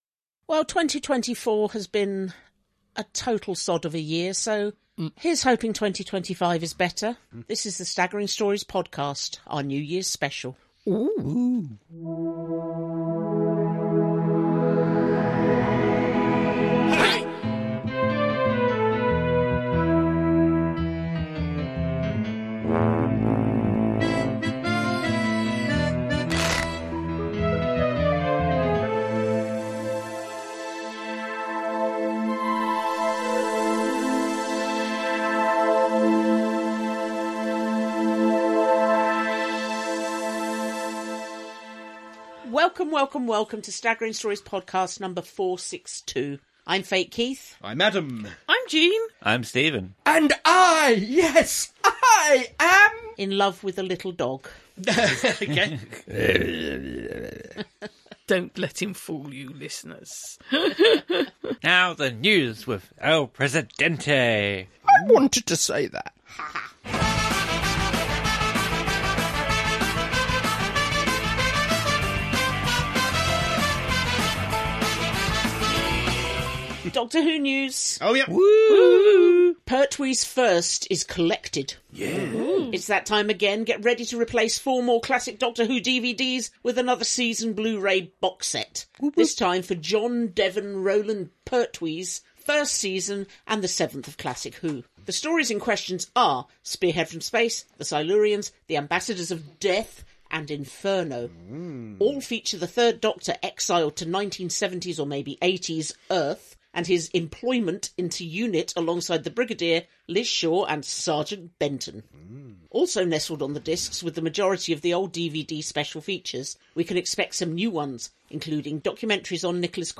00:00 – Intro and theme tune.
79:40 — End theme, disclaimer, copyright, etc.